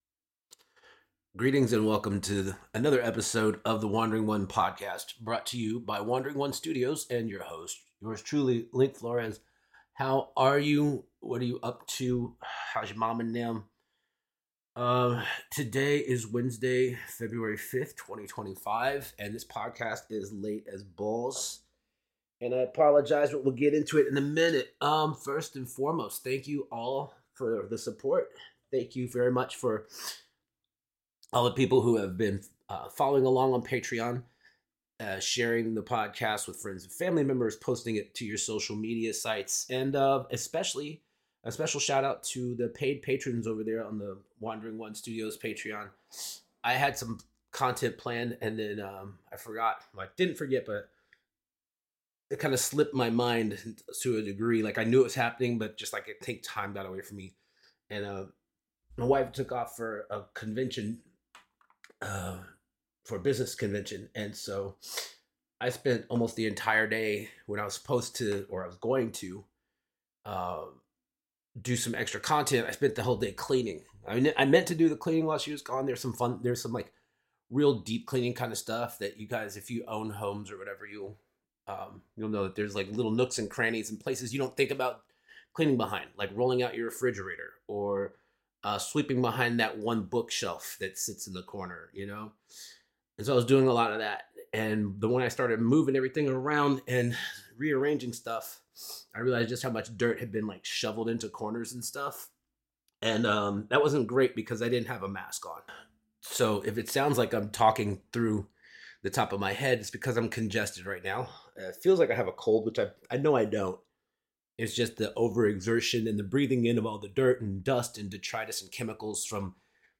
<<< WARNING: May contain adult language and thematic content. Listener discretion is advised. >>>
>>> *** Coughing breaks at 00:07:34 and 00:32:56, about 3 seconds each.